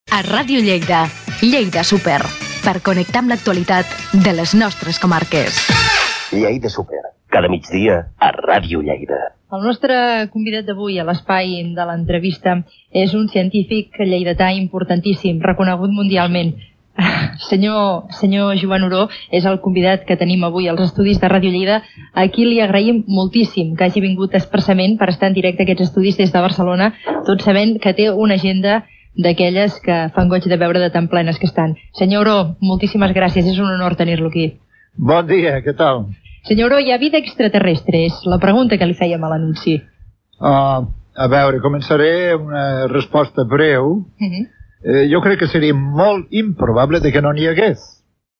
Indicatiu del programa, presentació de l'investigador Joan Oró i primera pregunta i resposta de l'entrevista
Info-entreteniment